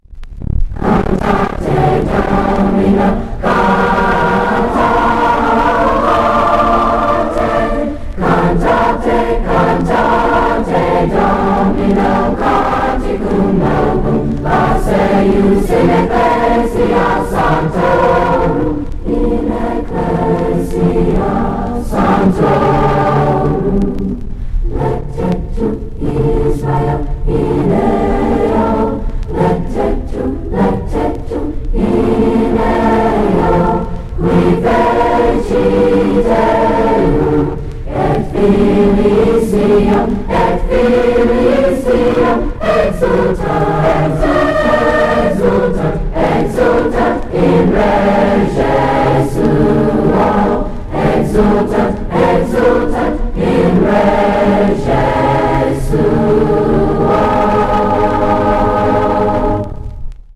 J.W. Cannon Jr. High School Chorus